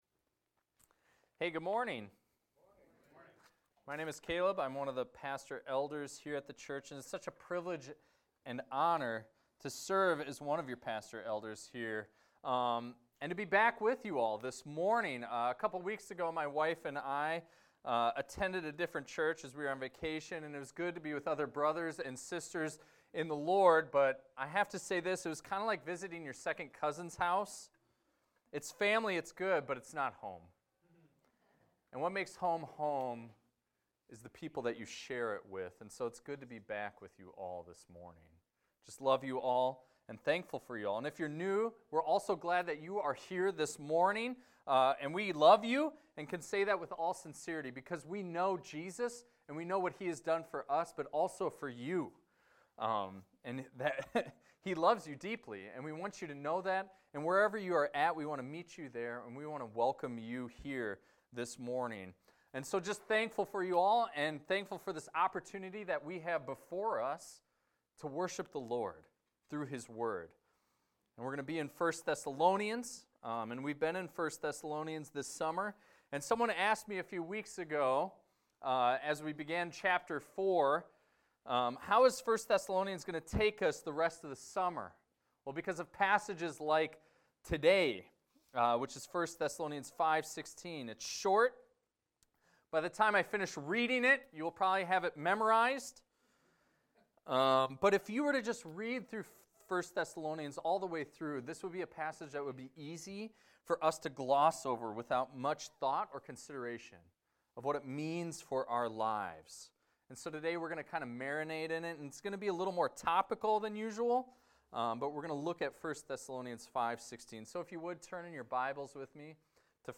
This is a recording of a sermon titled, "Rejoice Always."